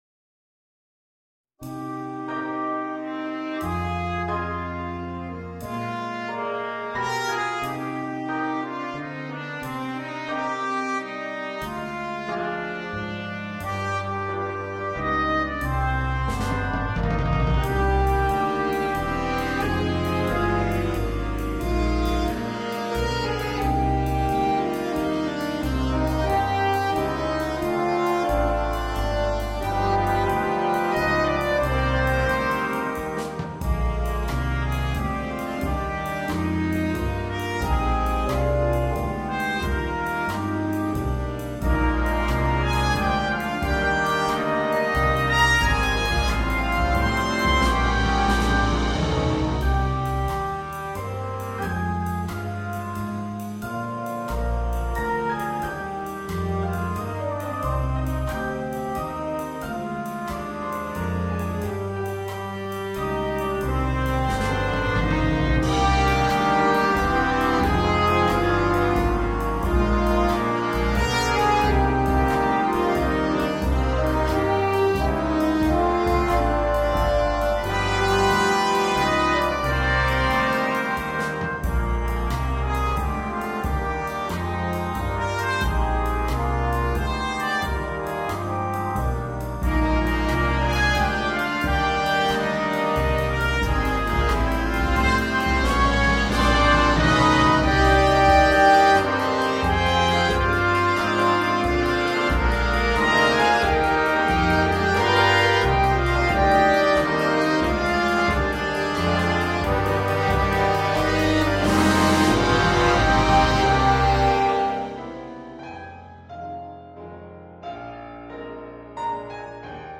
в переложении для биг-бэнда